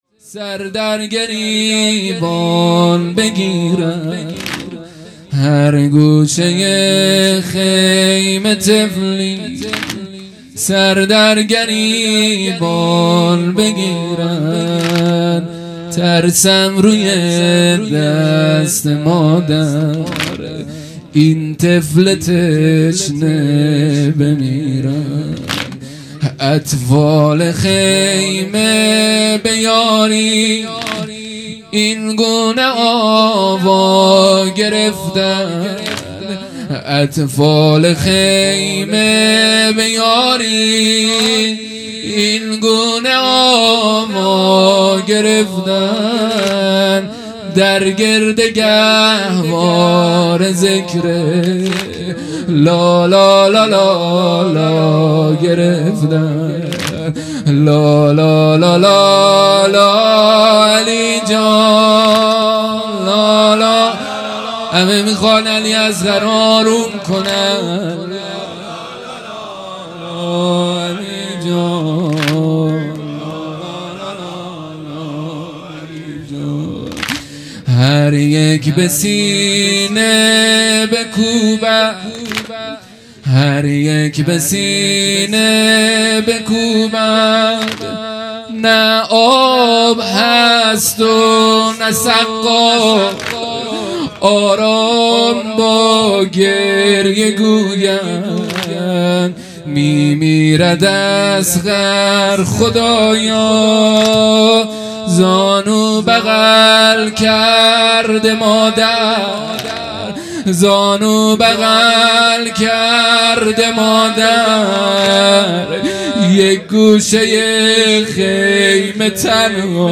محرم الحرام ۱۴۴۱ ، ۱۳۹۸ شب هفتم